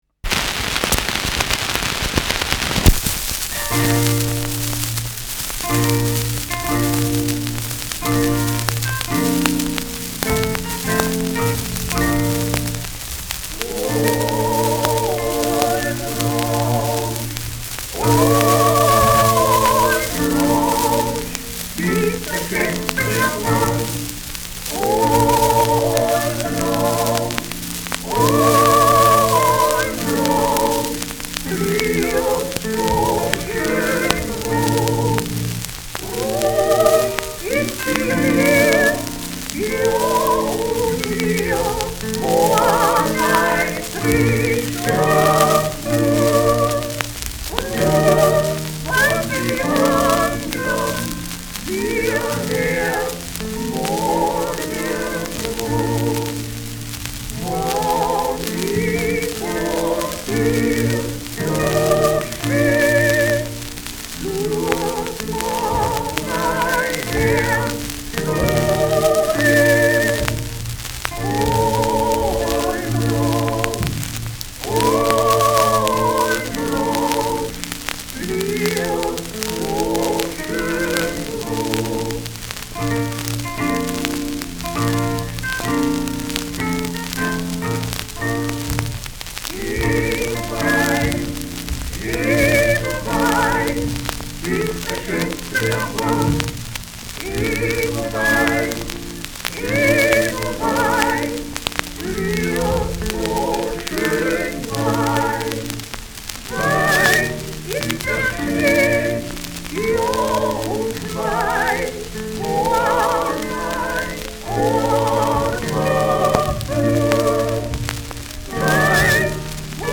Schellackplatte
Folkloristisches Ensemble* FVS-00015